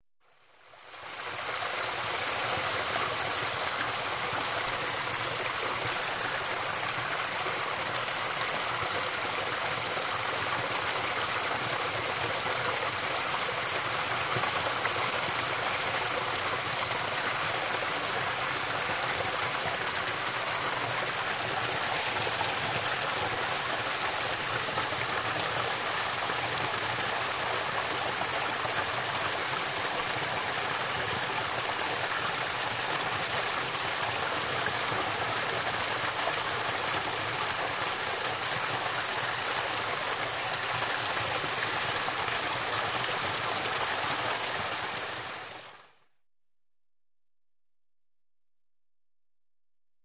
Звуки фонтана
Шум струи в саду